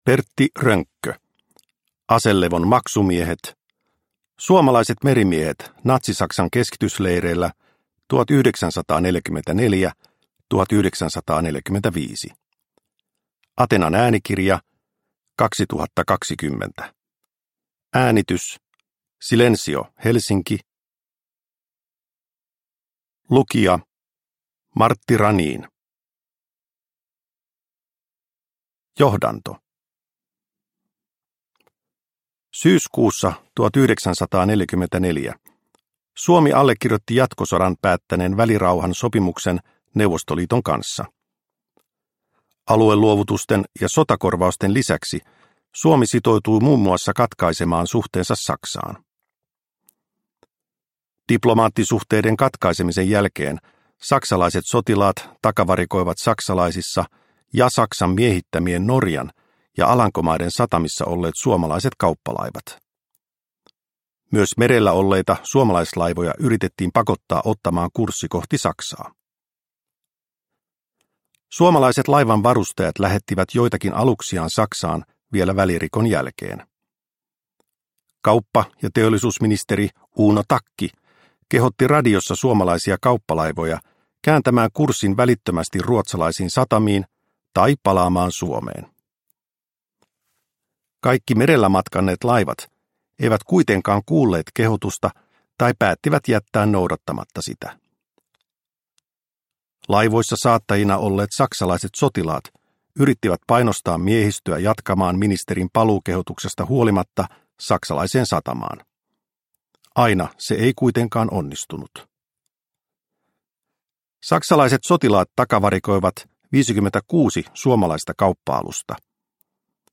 Aselevon maksumiehet – Ljudbok – Laddas ner